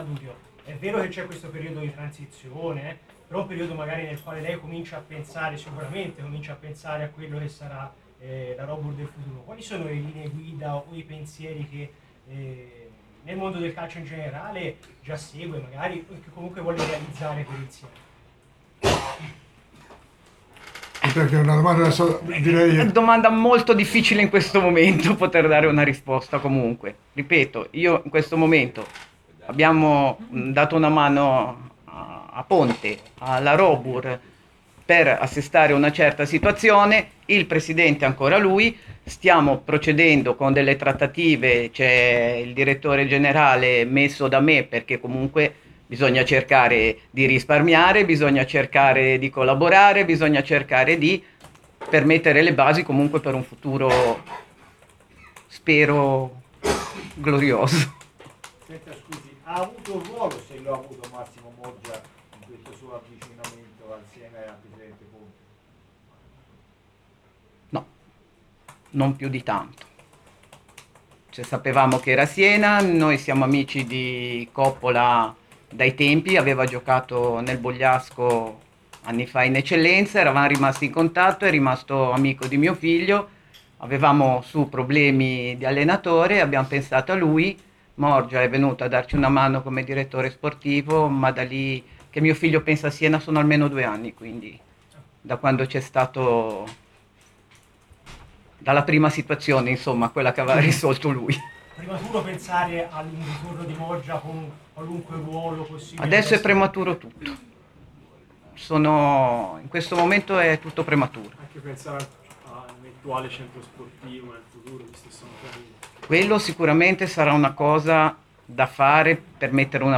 Robur Siena - Foggia 5-2, la conferenza fiume